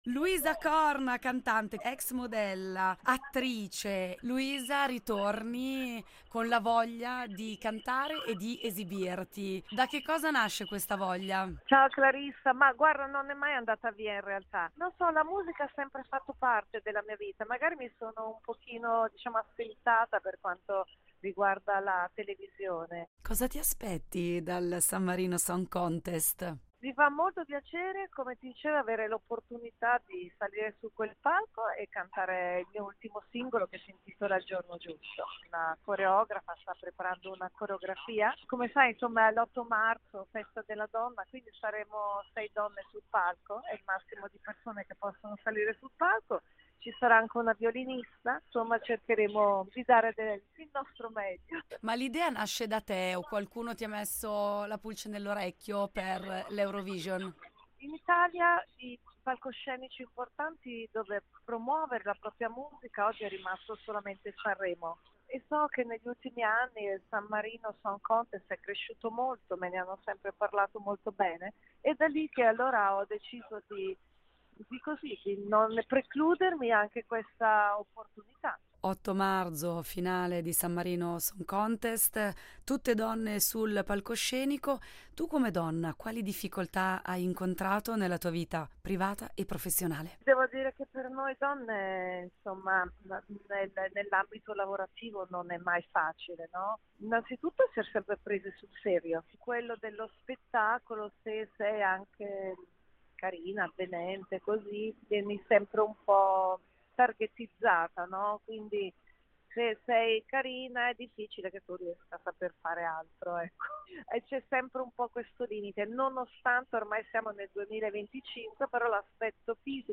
Incontro con Luisa Corna, cantante, attrice e conduttrice TV italiana